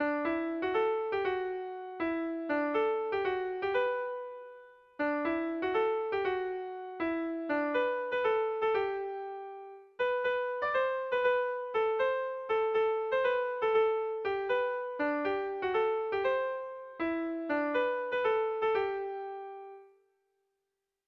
Gabonetakoa
A1A2BD